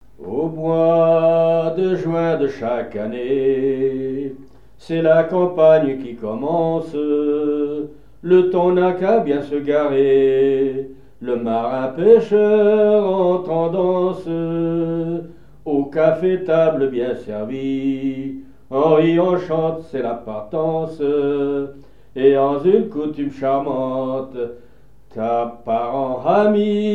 Genre strophique
La pêche au thon et des chansons maritimes
Pièce musicale inédite